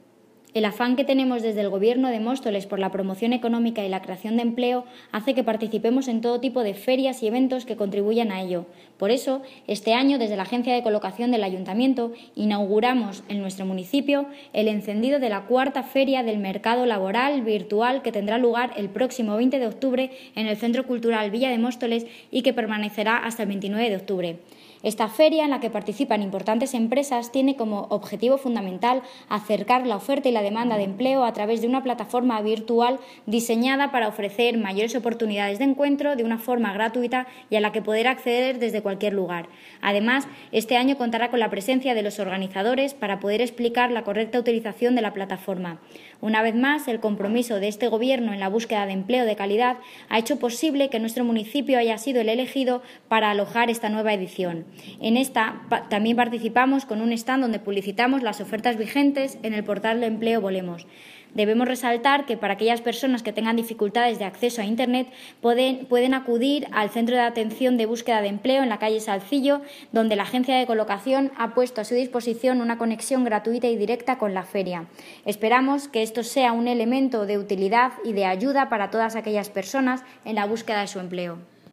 Audio - Jessica Antolín (Primera Teniente de Alcalde) Sobre Feria Mercado Laboral Virtual